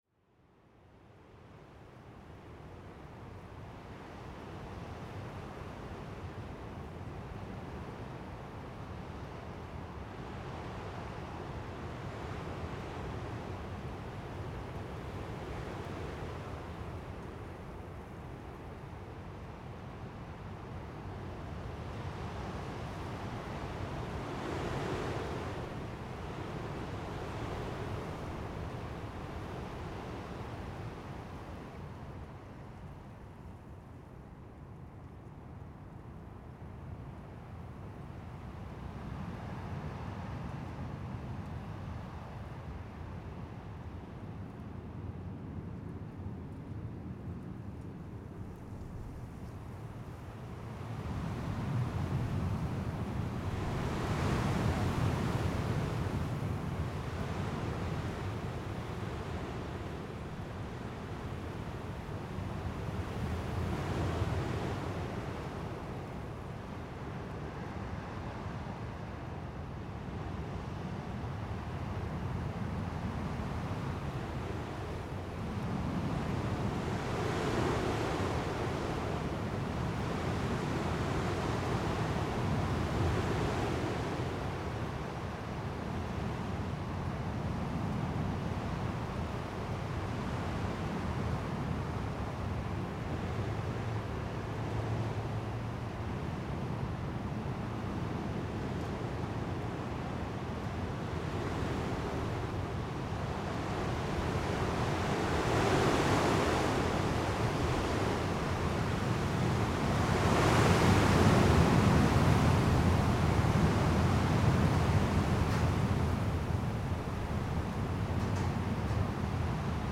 It was dry and the temp. about zero. But most of the time strong gust was blowing down from the glacier over the place. This recording was made during midday on the campsite where the gust was blowing through the naked branches.
Mics: RodeNT1a in NOS setup